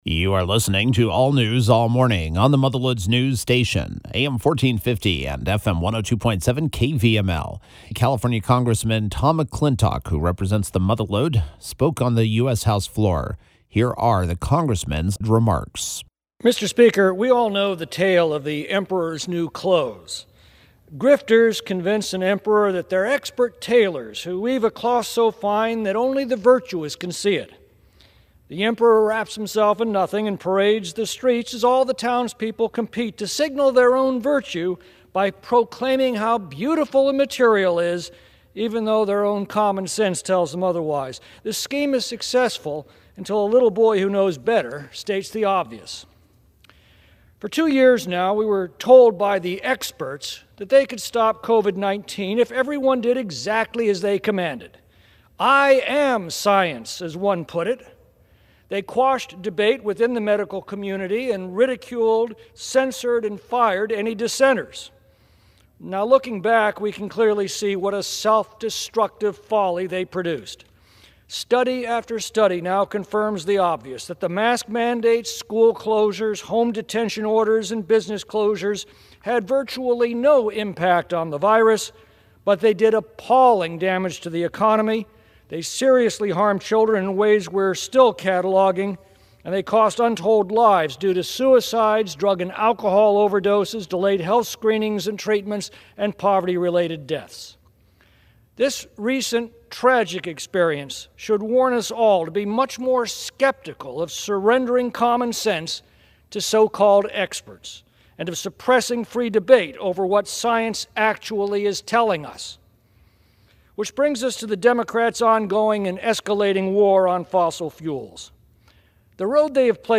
Congressman Tom McClintock (who represents the Mother Lode), recently delivered remarks on the House floor.